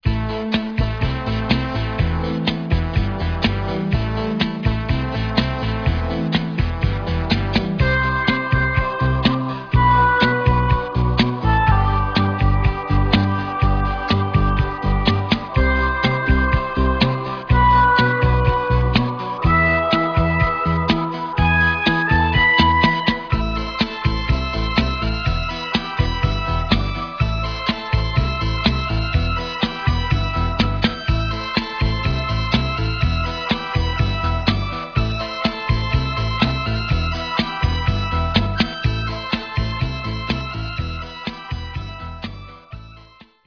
Le Minimoog avait une palette sonore très étendue...